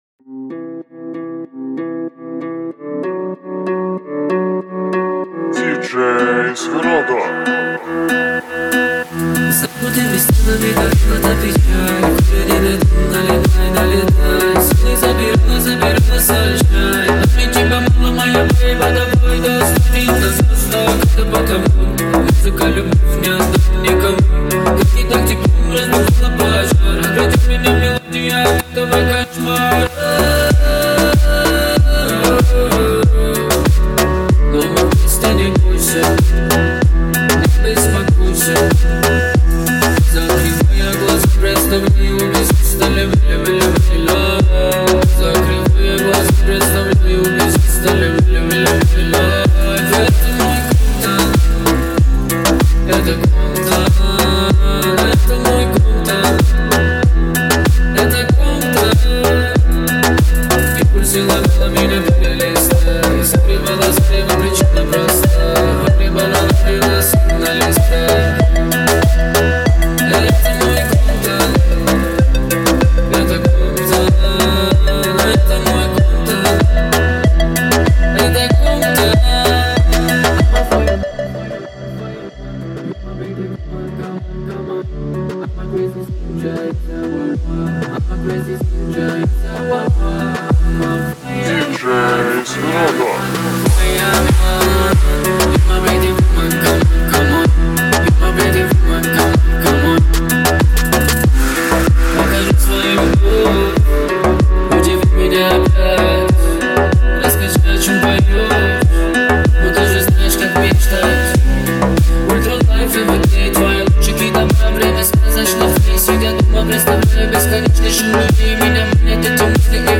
мощный трек в жанре поп-рок